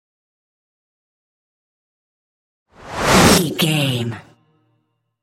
Trailer dramatic raiser short flashback
Sound Effects
In-crescendo
Atonal
tension
riser